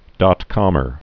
(dŏtkŏmər)